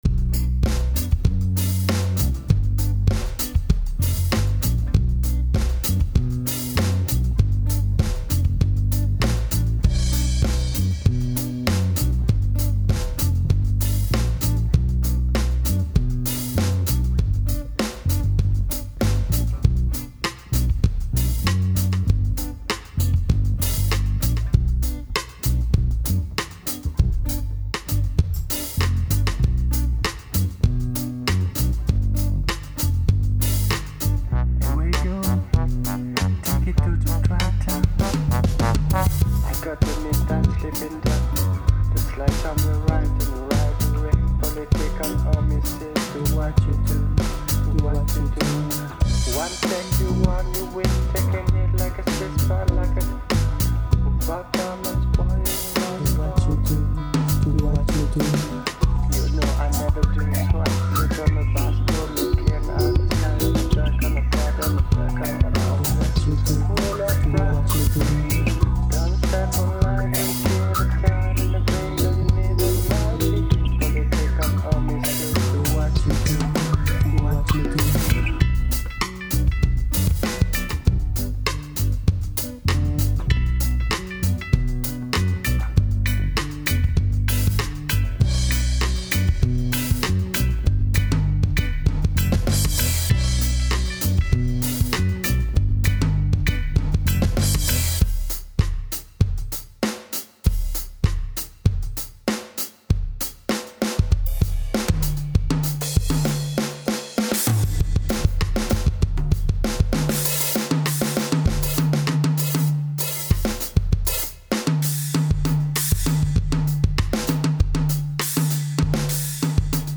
Et ce passage batterie ... biggrin.gif
j'aime bien ta ligne de basse:)
en revanche je trouve la batterie un chouilla trop en avant et trop "quantisée"
et si j'étais toi je ferai un peu de ménage dans ton solo pour le rendre un peu plus fluide wink.gif